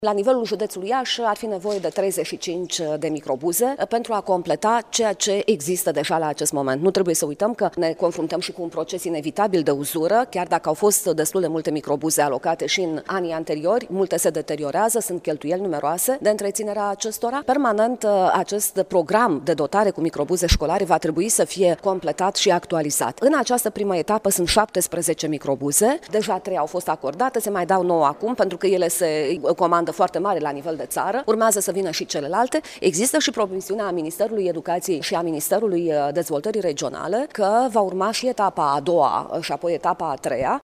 Declarația a fost făcută cu prilejul recepționării a 9 microbuze, după ce săptămâna trecută au fost primite altele 3.